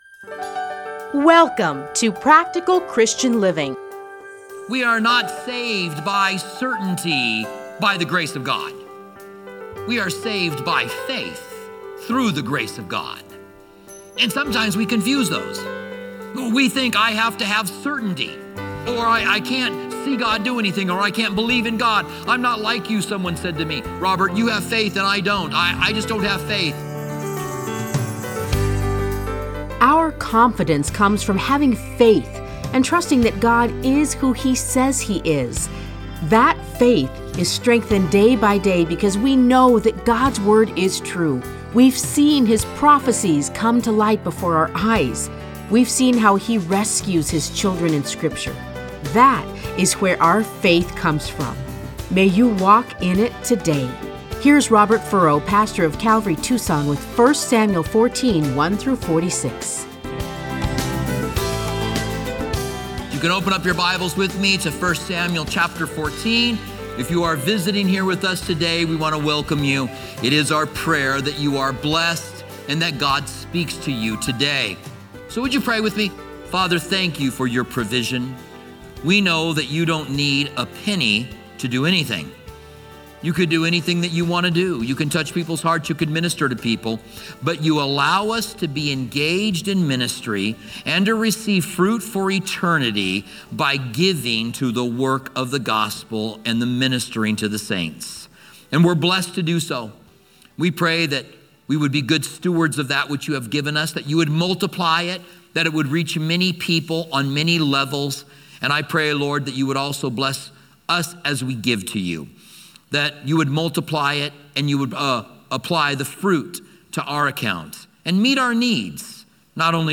Listen to a teaching from 1 Samuel 14:1-46.